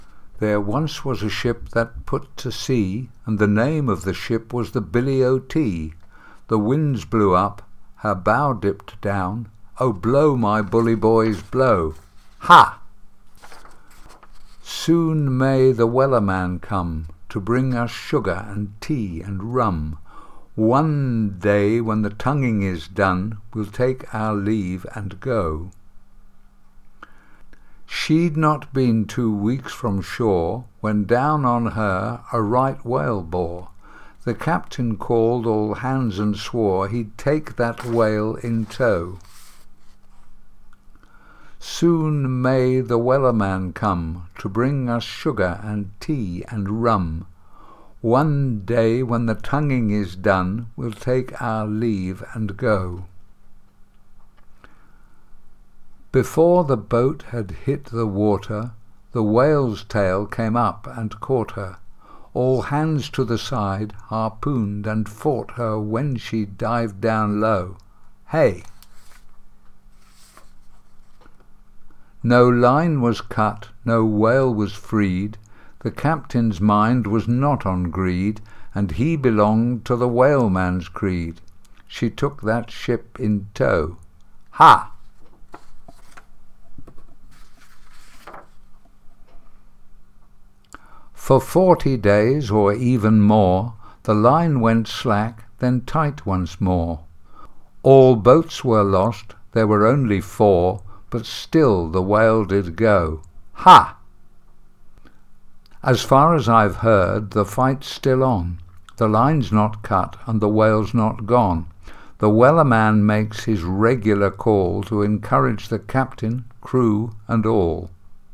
Fichiers de prononciation